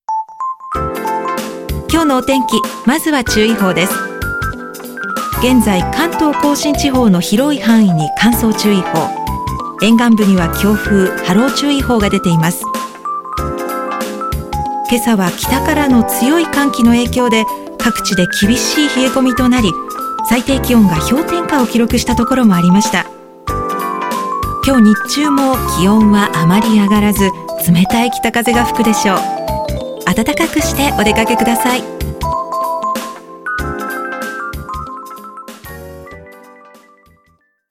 音声サンプル
柔らかく包み込むような声と、状況に合わせた安定感のある喋りが好評です。
クセの無いストレートな読みや、　研修ものなどはお任せください！